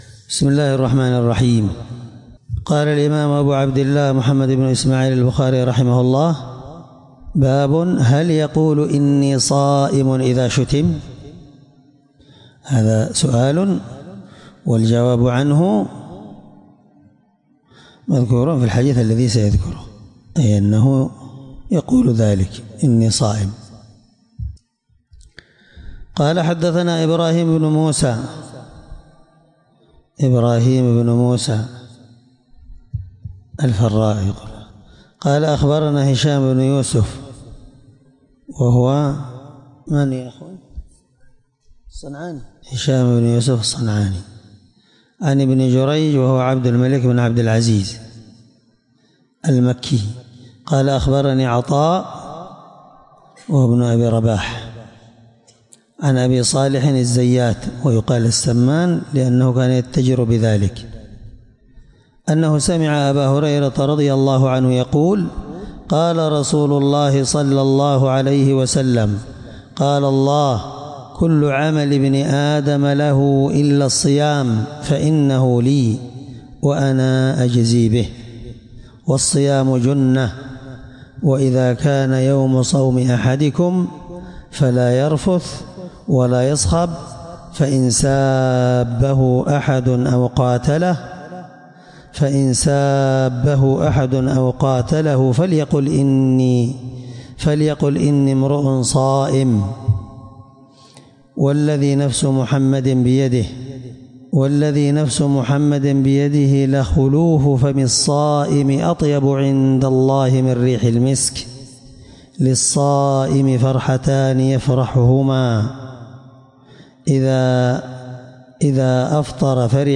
الدرس 9من شرح كتاب الصوم حديث رقم(1904 )من صحيح البخاري